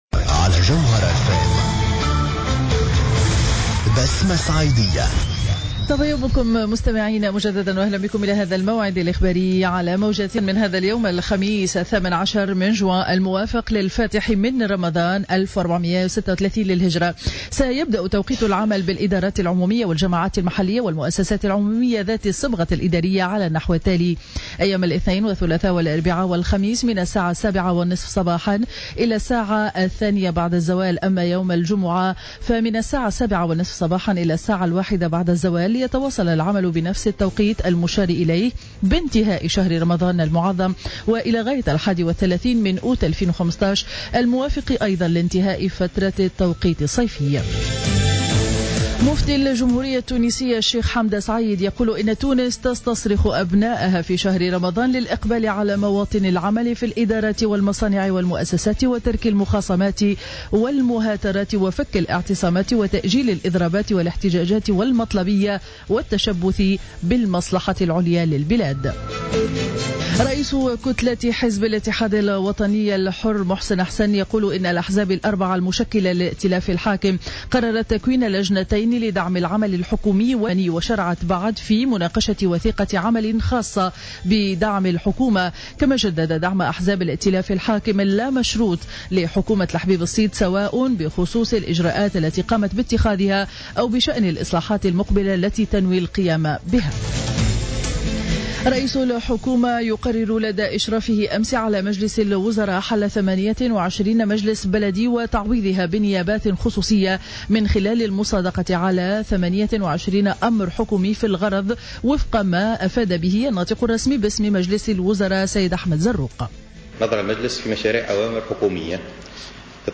نشرة أخبار السابعة صباحا ليوم الخميس 18 جوان 2015